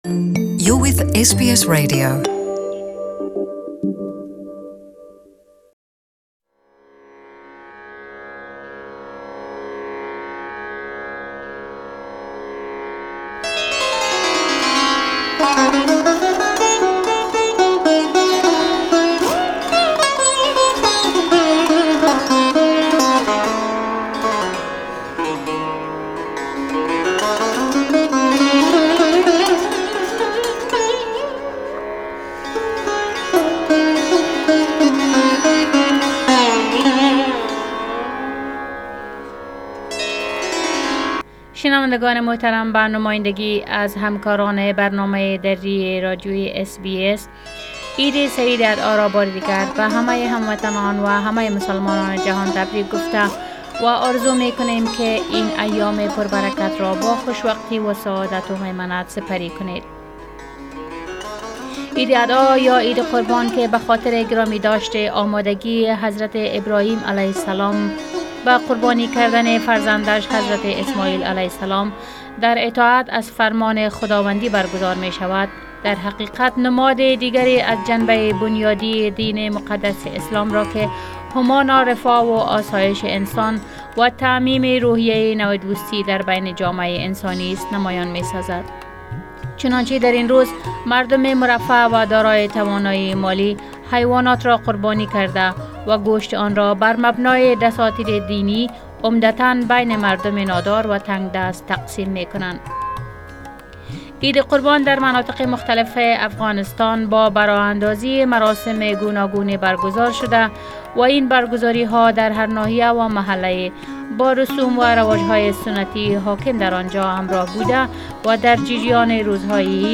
Eid Special program and interview